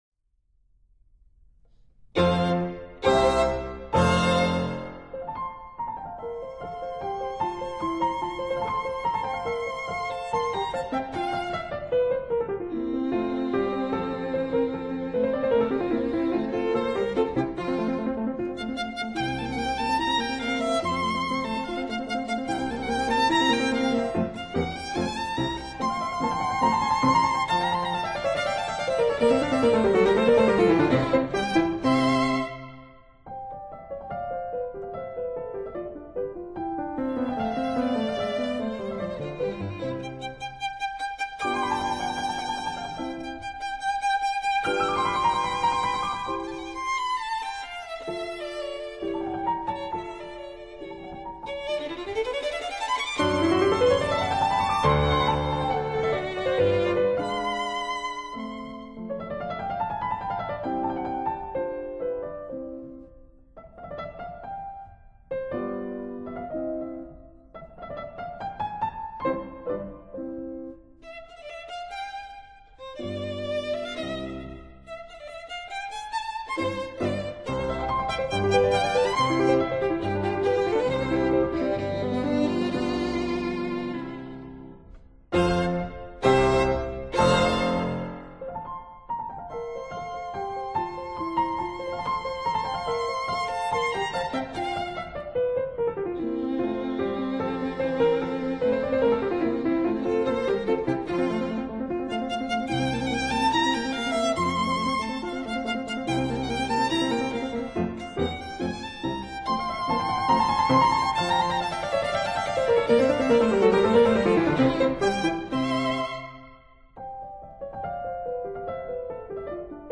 钢琴 piano